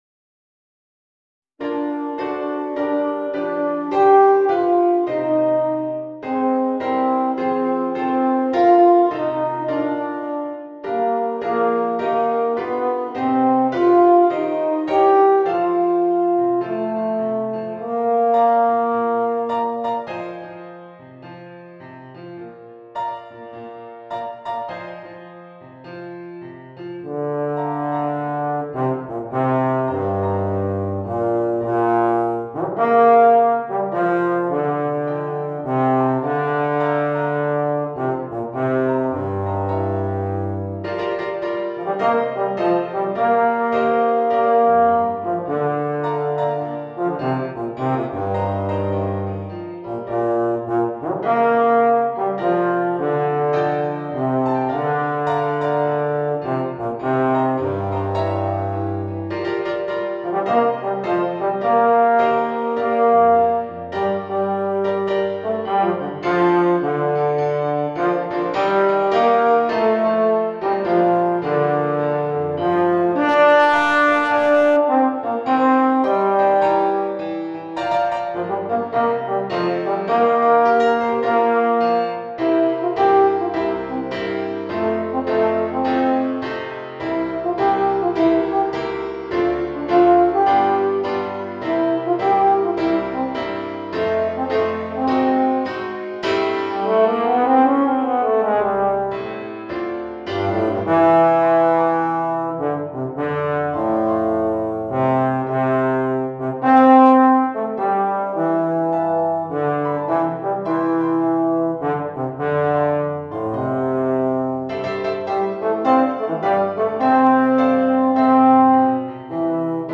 （ユーフォニアム+ピアノ）